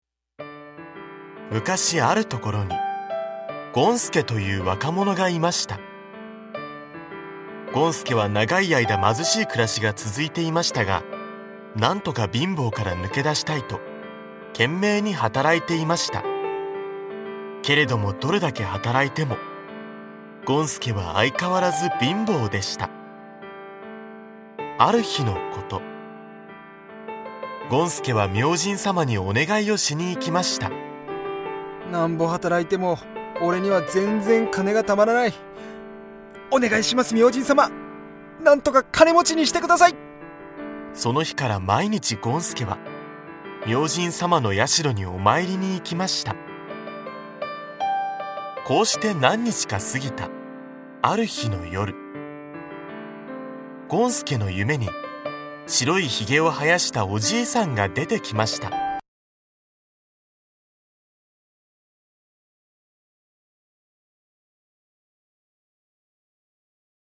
[オーディオブック] 鼻高おうぎ 世界の童話シリーズその59
大人も子どもも一緒になって、多彩なキャストと、楽しい音楽でお楽しみ下さい。
大人も子供も楽しめる童話オーディオブックを、多彩なキャストとBGMでお届けします。